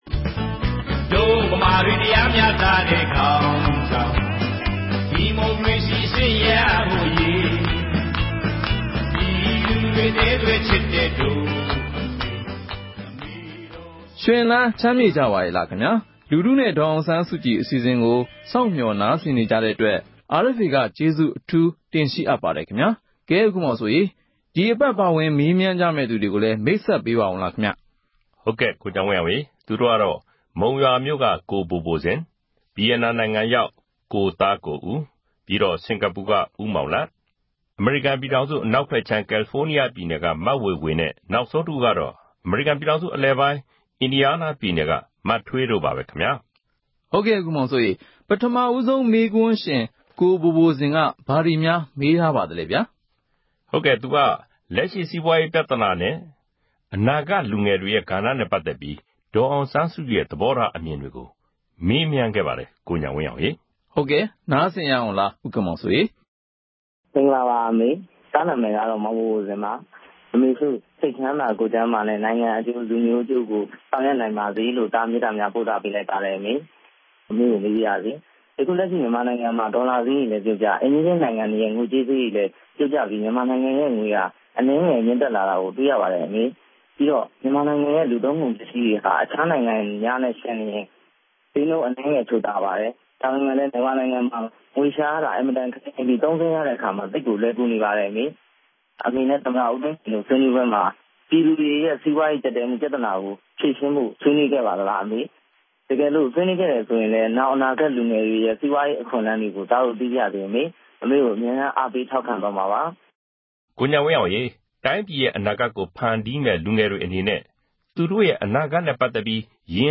လူထုနဲ့ ဒေါ်အောင်ဆန်းစုကြည် အစီအစဉ်ကို RFA က အပတ်စဉ် သောကြာနေ့ ညတိုင်းနဲ့ ဗုဒ္ဓဟူးနေ့ မနက်တိုင်း တင်ဆက်နေပါပြီ။ ဒီ အစီအစဉ်ကနေ ပြည်သူတွေ သိချင်တဲ့ မေးခွန်းတွေကို ဒေါ်အောင်ဆန်းစုကြည် ကိုယ်တိုင် ဖြေကြားပေးမှာ ဖြစ်ပါတယ်။